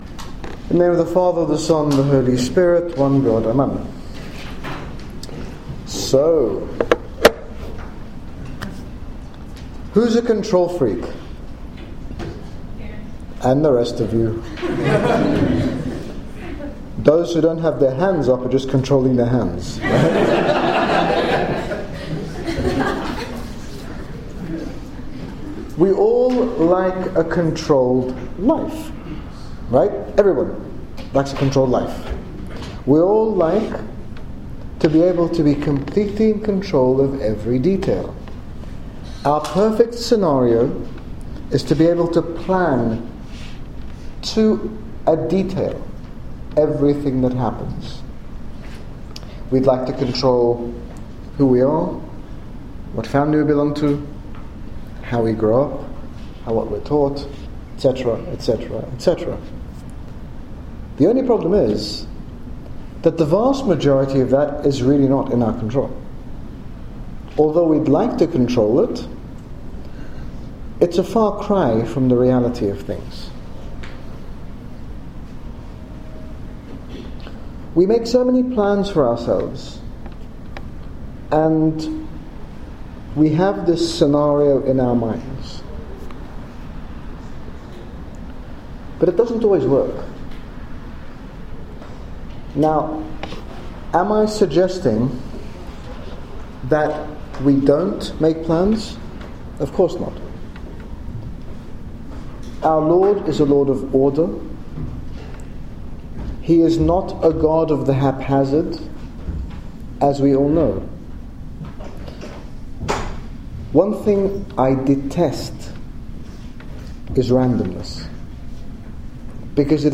We often like to control every aspect of our lives, and struggle to place our trust and lives fully into the hands of God. In this talk, His Grace Bishop Angaelos explains the benefits of placing our lives in the hands of our heavenly Father, Who will always lead us down the best path for each one of us. Download Audio Read more about Dealing with the unexpected - NWCYC 2014 - Talk 3